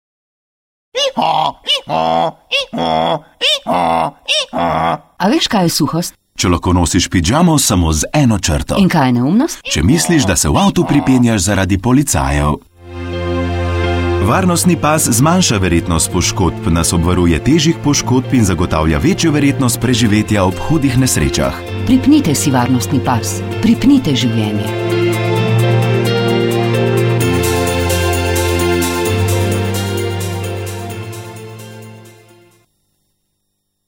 Svet za preventivo in varnost v cestnem prometu je ob tej akciji pripravil tudi preventivne radijske oglase, ki pozivajo k uporabi varnostnega pasu.